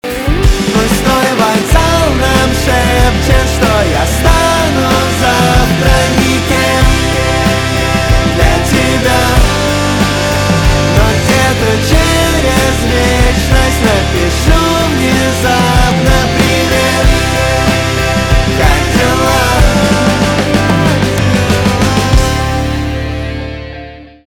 русский рок , гитара , барабаны , грустные
чувственные